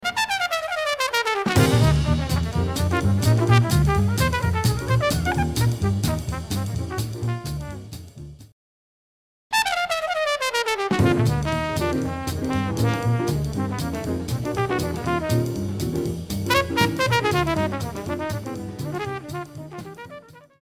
A bouncy, jaunty blues head.